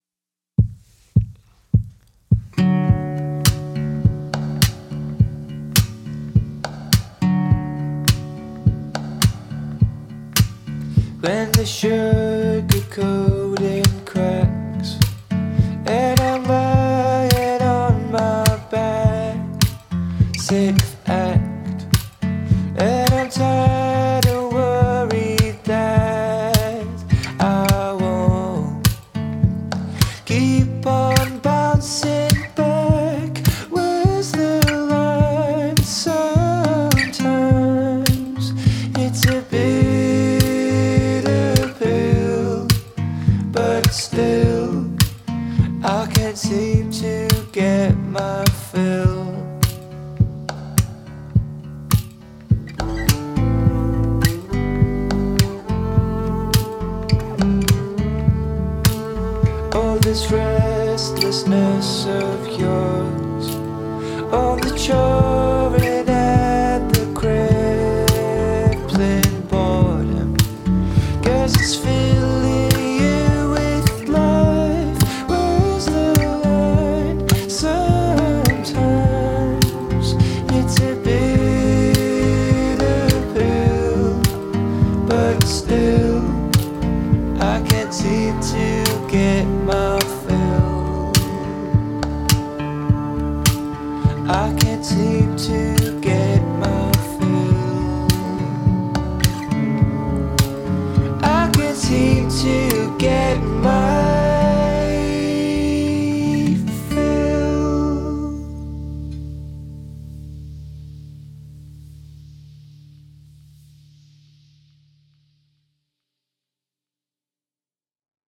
Bowed Double Bass